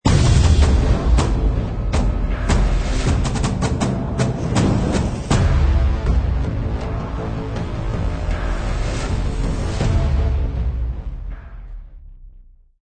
44.1 kHz 震撼大气片头音乐 全站素材均从网上搜集而来，仅限于学习交流。